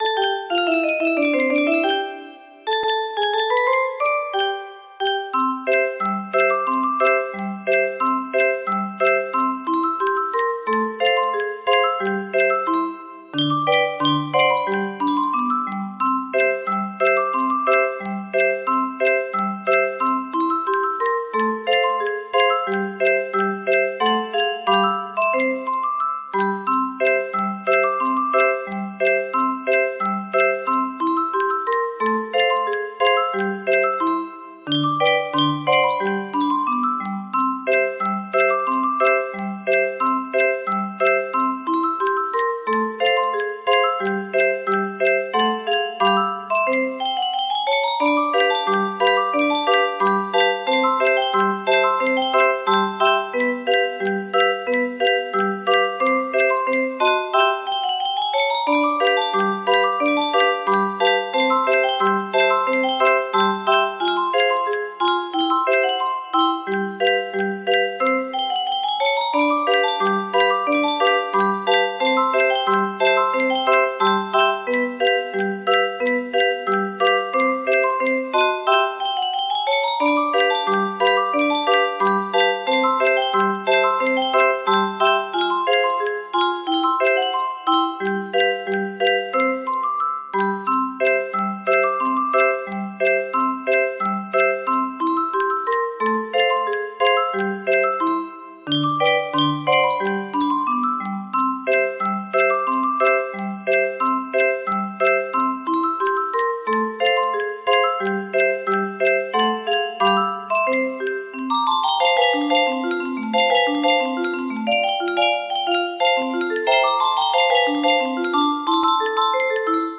Scott Joplin Pineapple Rag music box melody
Yay! It looks like this melody can be played offline on a 30 note paper strip music box!